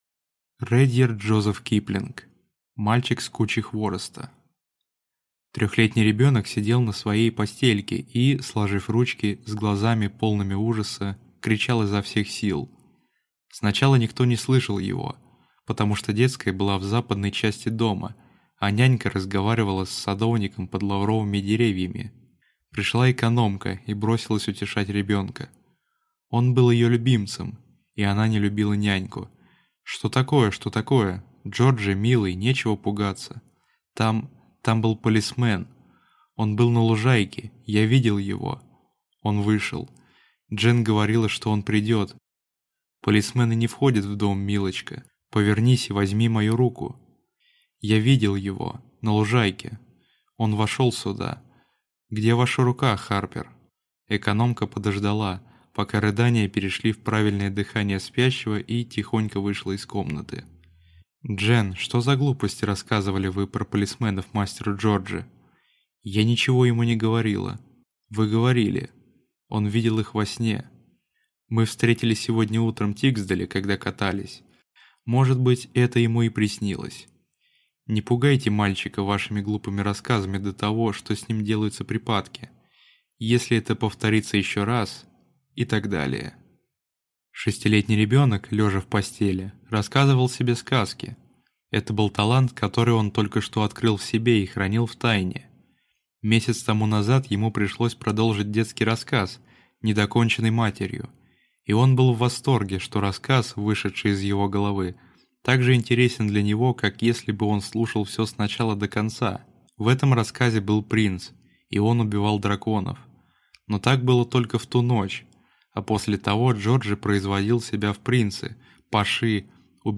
Аудиокнига Мальчик с кучи хвороста | Библиотека аудиокниг